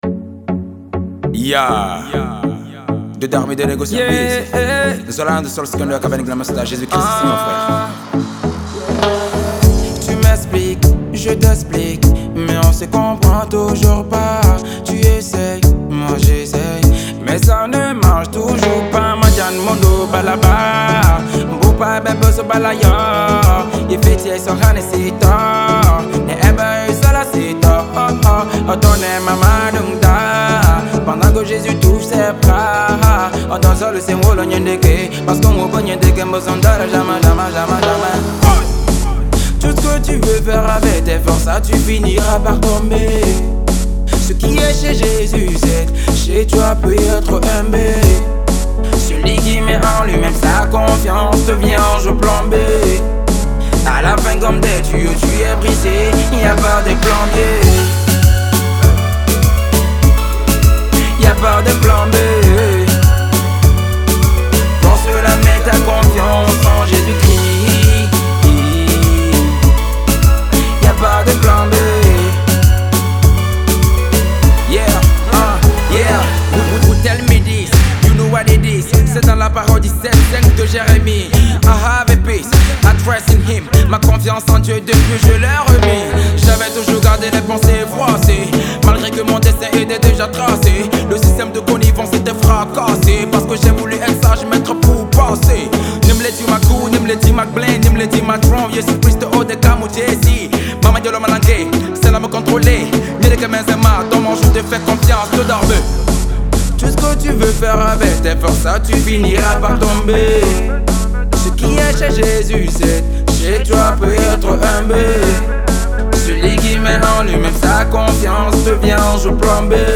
artiste togolais de gospel urbain.